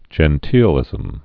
(jĕn-tēlĭzəm)